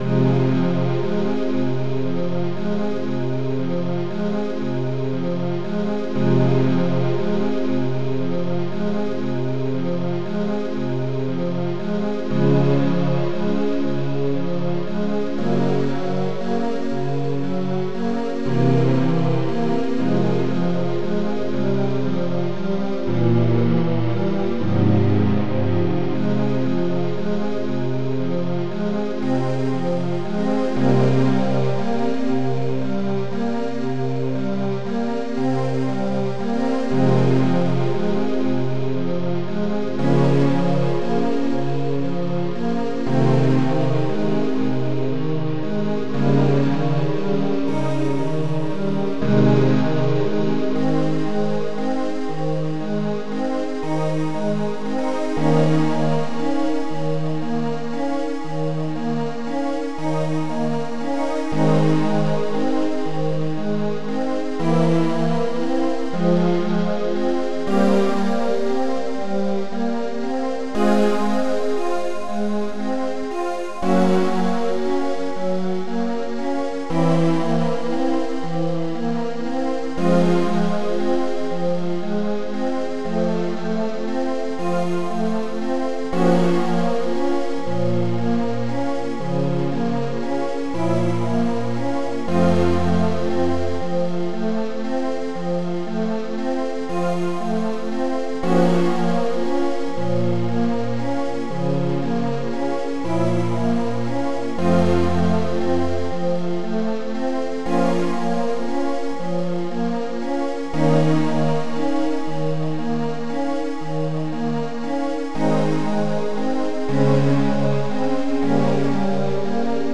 Composer 669 Module
Moonlight Sonata... Type 669 (Composer 669) Tracker Composer 669 Tracks 8 Samples 1 Patterns 29 Instruments Synth Voice Moonlight Sonata...